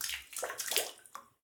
water-04
bath bathroom bathtub bubble burp click drain drip sound effect free sound royalty free Nature